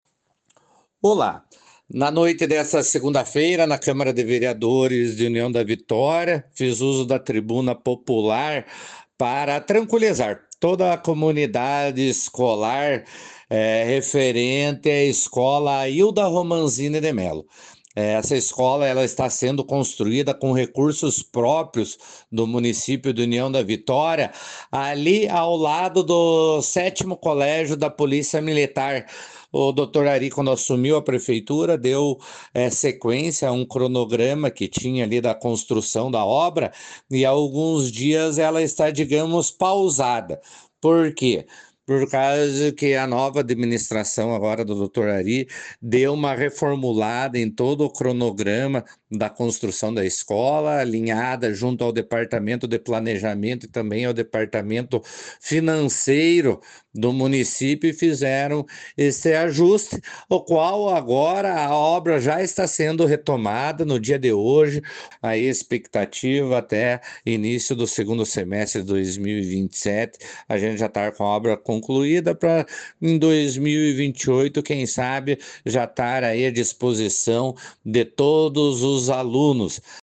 Cordovan Neto em seu pronunciamento